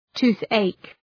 Προφορά
{‘tu:ɵeık}